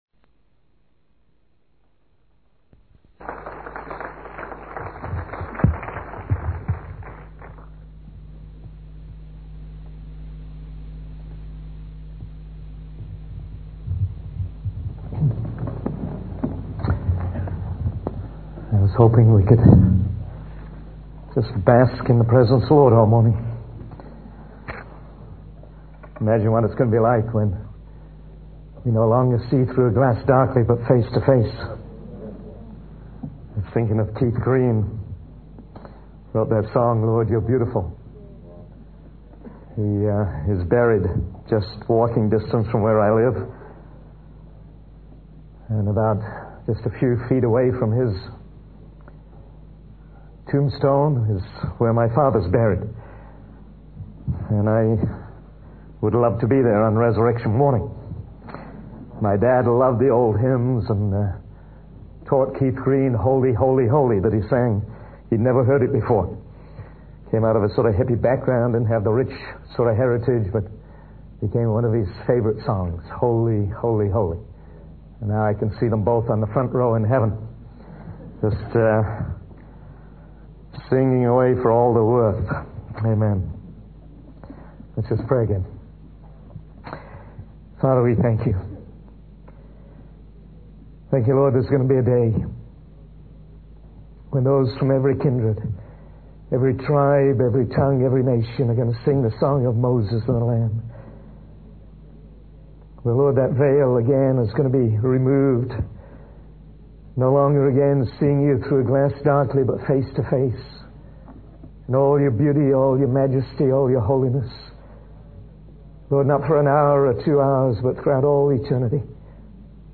In this sermon, the preacher discusses the importance of submission in various areas of life.